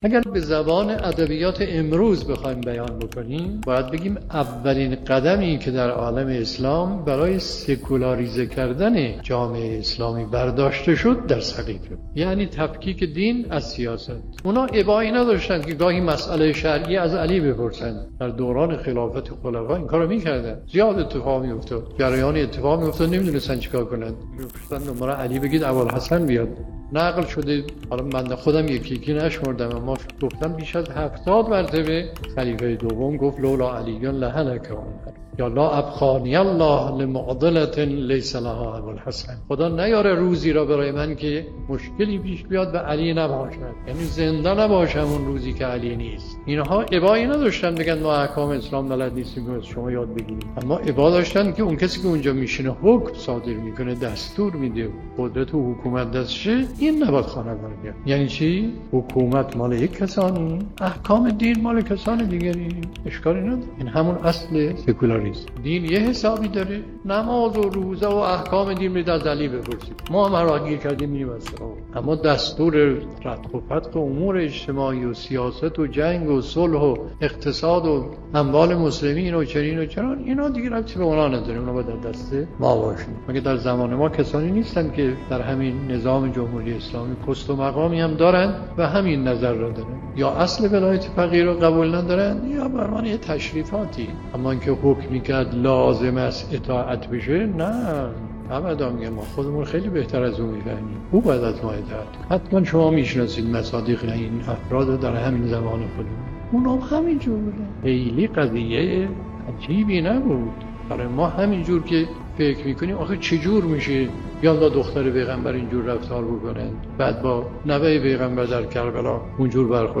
به گزارش خبرگزاری حوزه، مرحوم علامه مصباح در یکی از سخنرانی‌ها به موضوع «سکولاریزه کردن جامعه اسلامی» اشاره کردند که تقدیم شما فرهیختگان می‌شود.